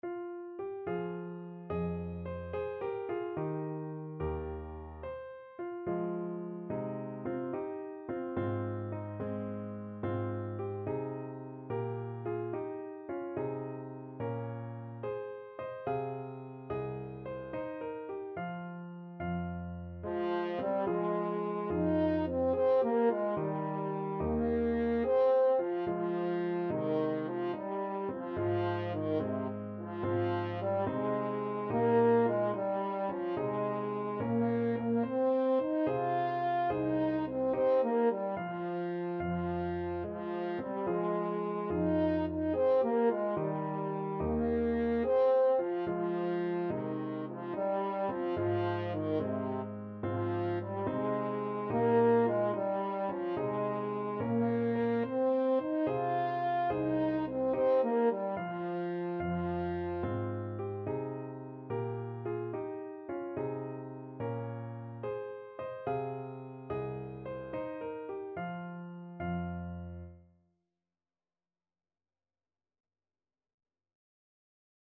9/8 (View more 9/8 Music)
Gently .=c.72
Traditional (View more Traditional French Horn Music)
world (View more world French Horn Music)